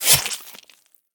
combat / weapons / default_swingable / flesh3.ogg
flesh3.ogg